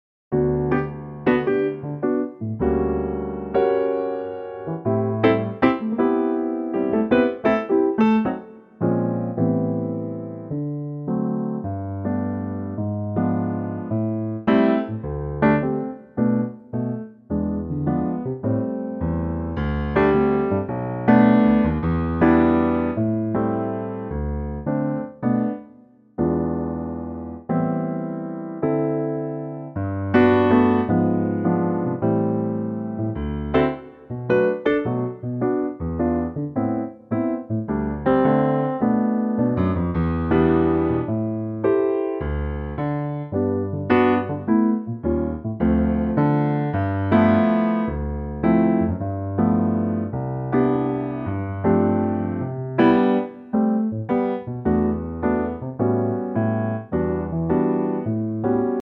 Unique Backing Tracks
key - G to Ab - vocal range - B to Eb